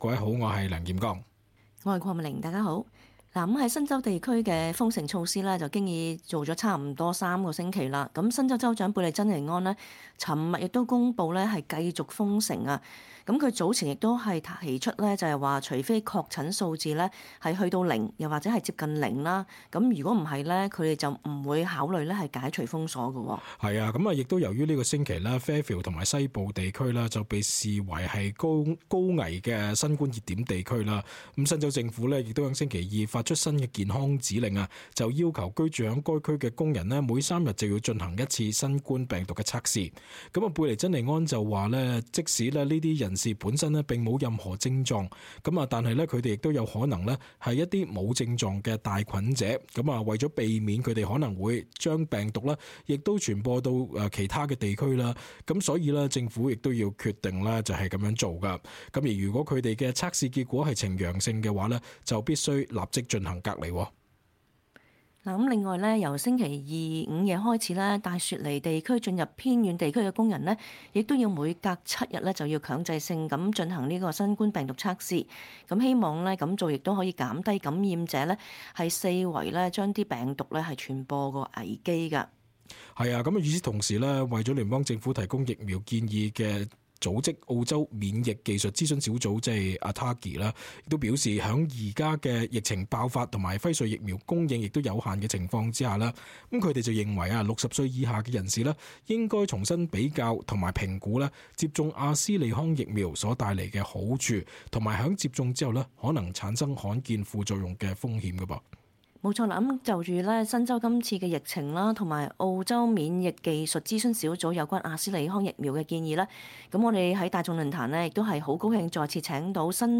cantonese_-talk_back_july_15.mp3